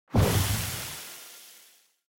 دانلود آهنگ آب 18 از افکت صوتی طبیعت و محیط
دانلود صدای آب 18 از ساعد نیوز با لینک مستقیم و کیفیت بالا
جلوه های صوتی